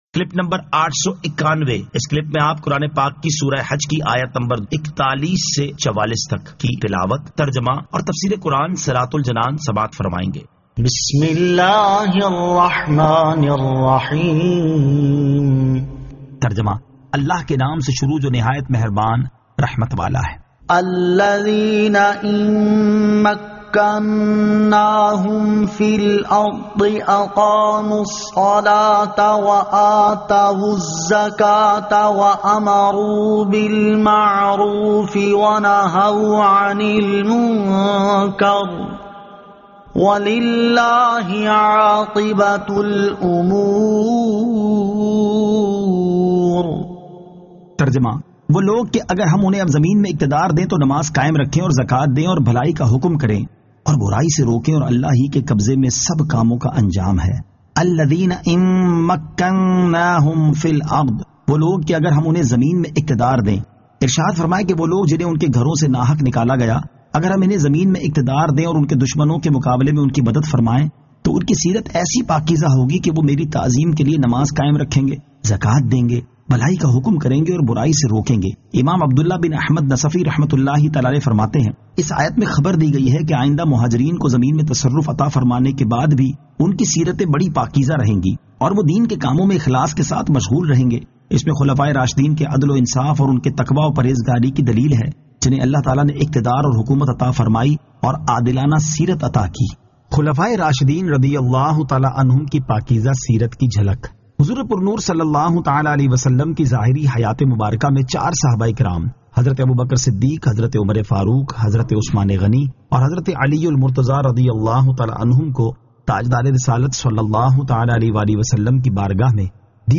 Surah Al-Hajj 41 To 44 Tilawat , Tarjama , Tafseer